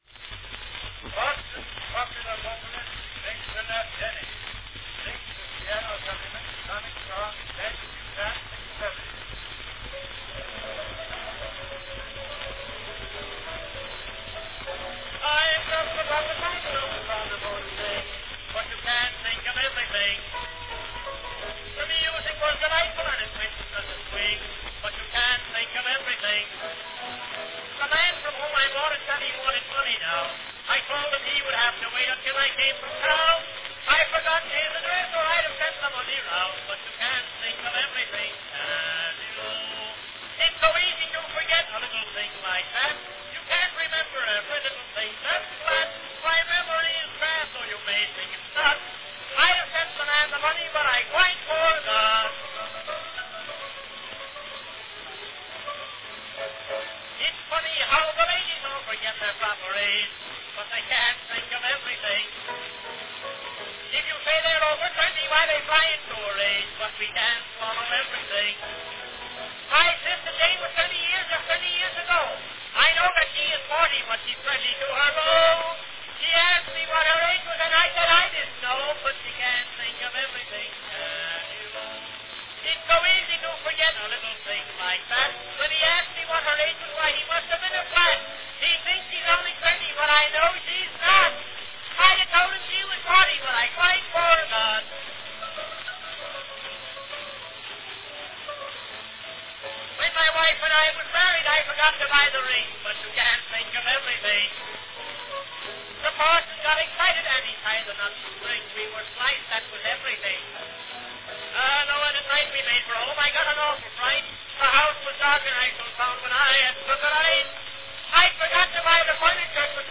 An early comic song recording from 1893, You Can't Think of Everything, by the great William F. Denny.
Category Comic song
Performed by William F. Denny
Announcement "Boston's popular vocalist, Nathan F. Denny, sings, with piano accompaniment, the comic song entitled 'You Can't Think of Everything'."
Denny often announced his own recordings – oddly, here he seems to introduce himself as Nathan F. Denny.